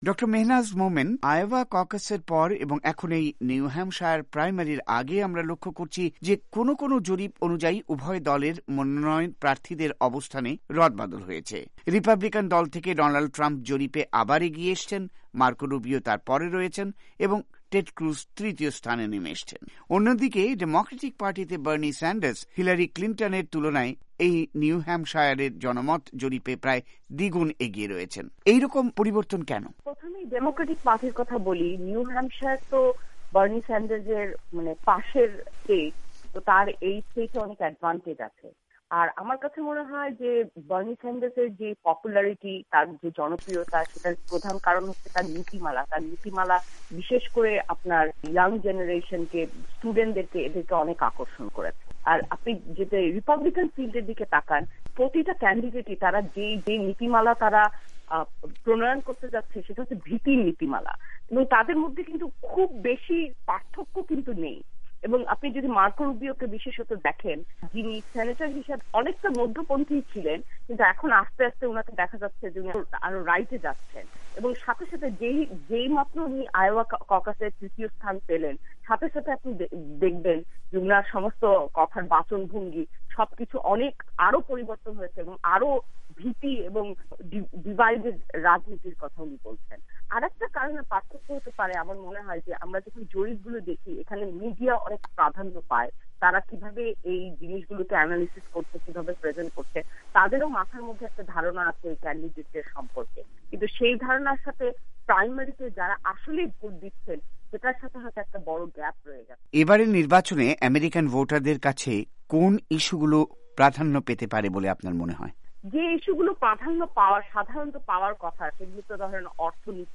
এক বিশ্লেষণমূলক সাক্ষাৎকারে